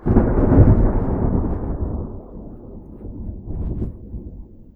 tenkoku_thunder_distant01.wav